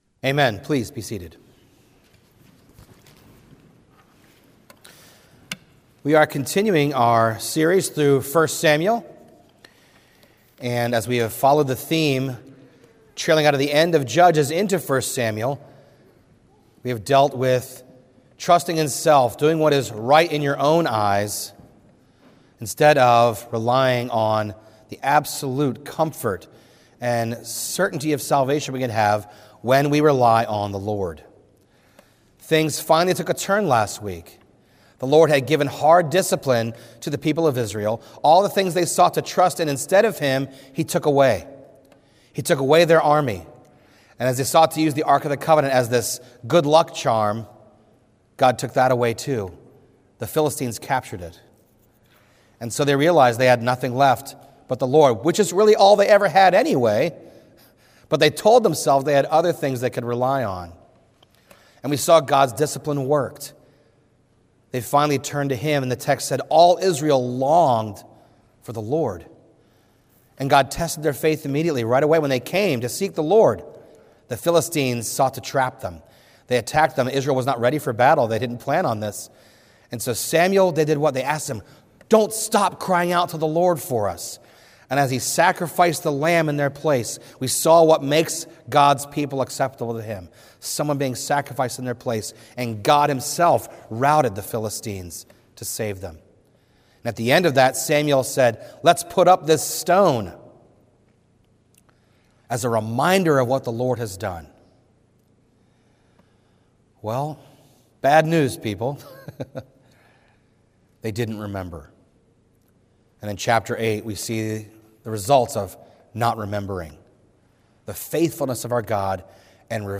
A message from the series "1 Samuel."